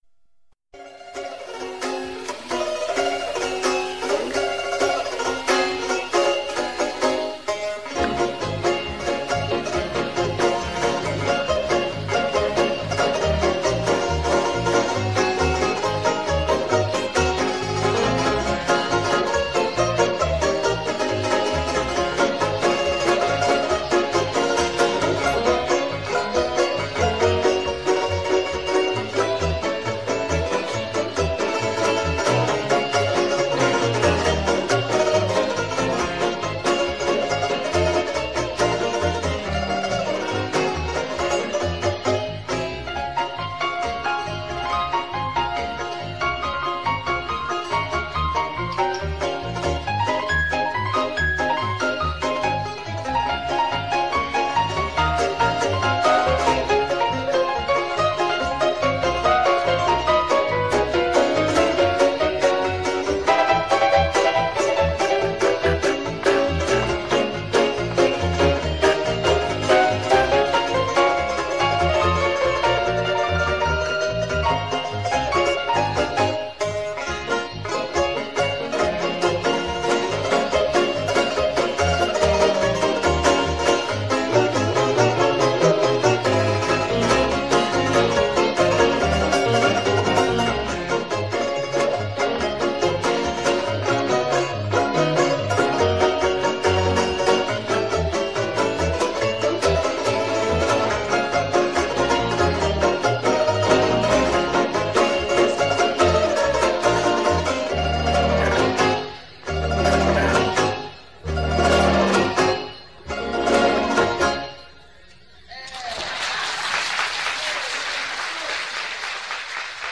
plectrun banjo